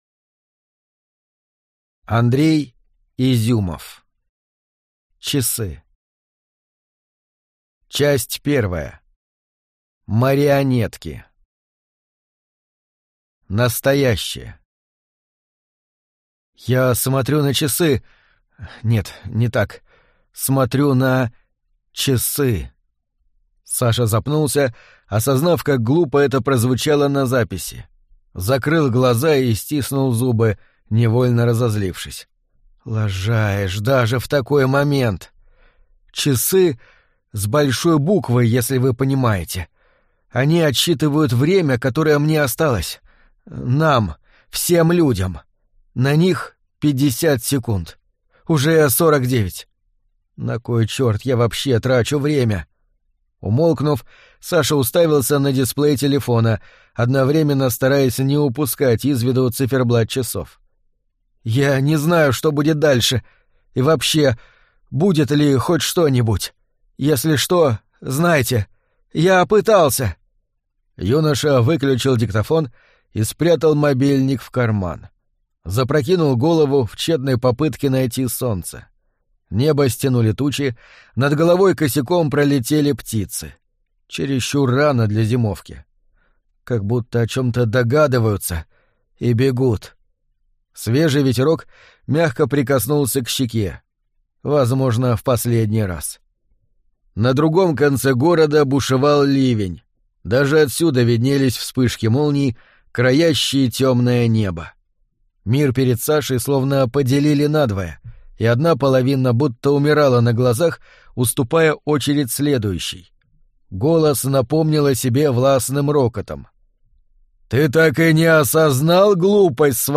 Аудиокнига Часы | Библиотека аудиокниг